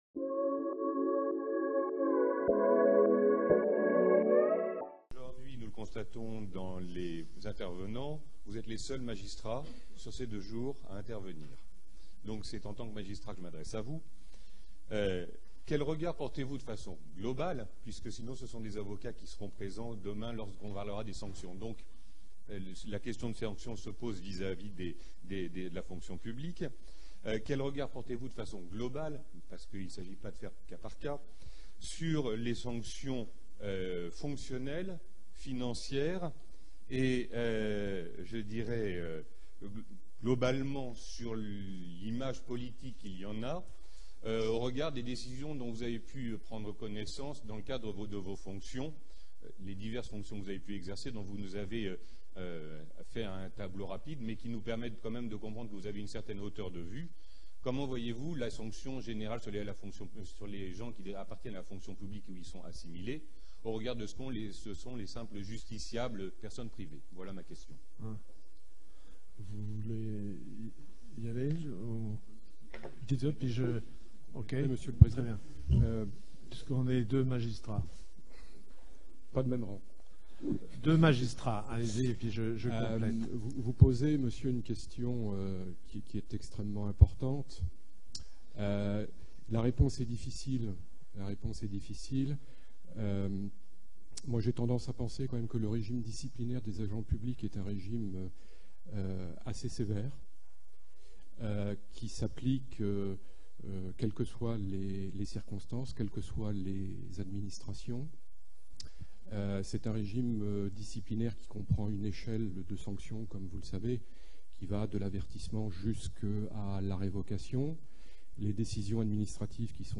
Comment les responsables publics doivent-ils répondre de leurs actes ? Discussion entre la tribune et la salle | Canal U